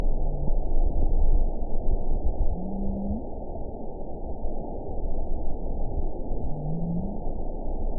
event 915581 date 12/06/22 time 12:41:47 GMT (2 years, 10 months ago) score 9.05 location TSS-AB05 detected by nrw target species NRW annotations +NRW Spectrogram: Frequency (kHz) vs. Time (s) audio not available .wav